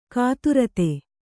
♪ kāturate